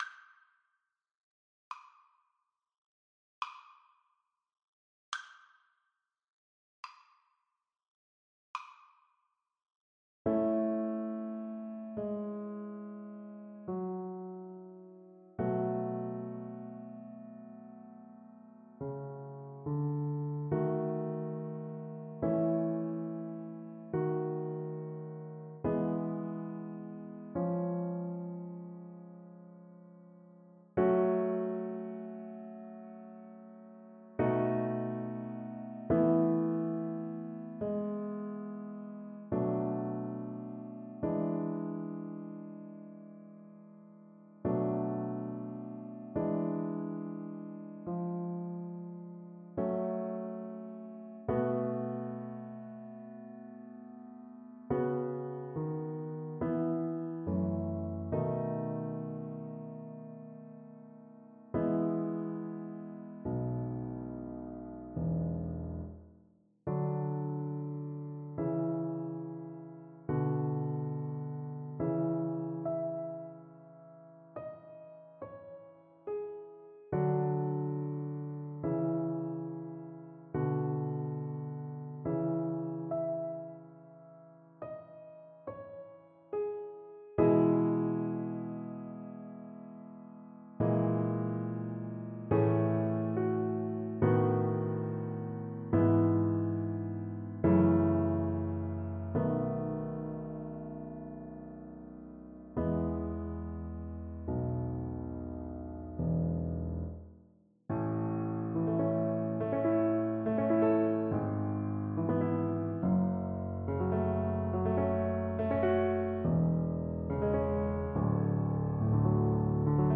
Andante sostenuto ( = 54)
3/4 (View more 3/4 Music)
Db5-Db7
Violin  (View more Intermediate Violin Music)
Classical (View more Classical Violin Music)